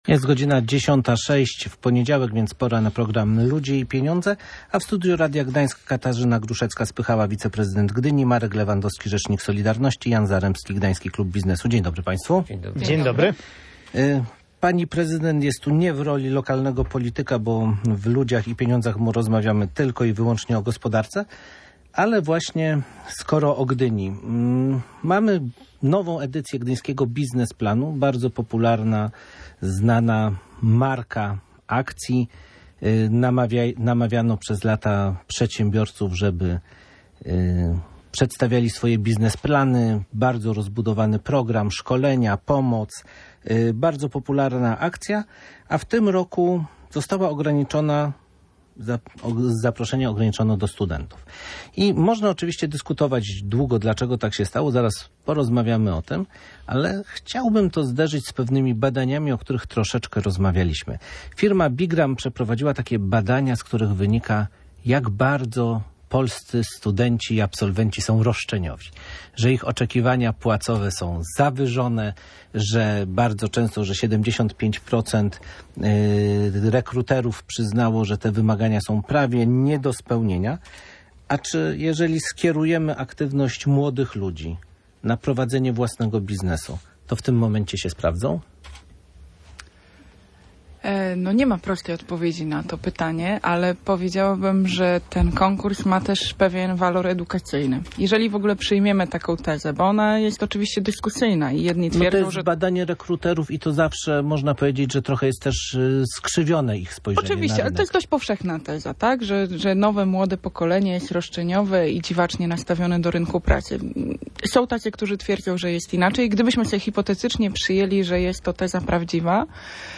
Tam trzeba się wykazać – mówi w audycji Ludzie i pieniądze wiceprezydent Gdyni Katarzyna Gruszecka-Spychała.